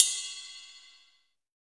D2 RIDE-04.wav